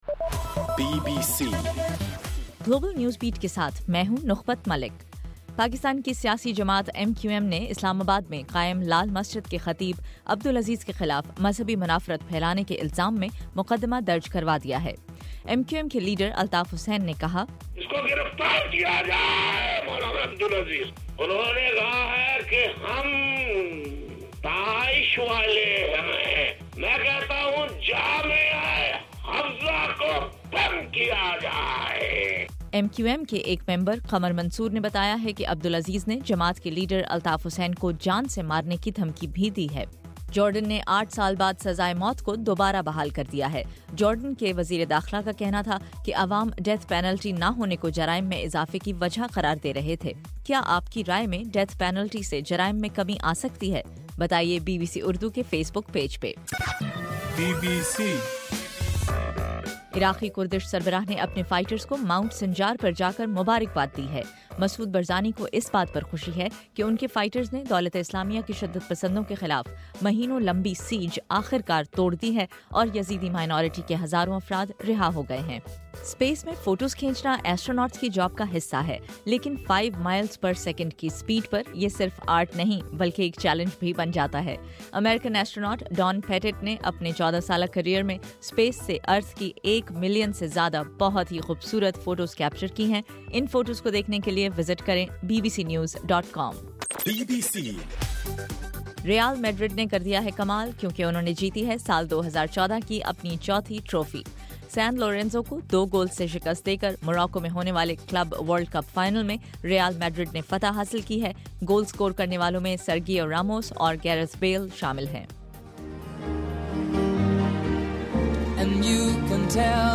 دسمبر21: رات 10 بجے کا گلوبل نیوز بیٹ بُلیٹن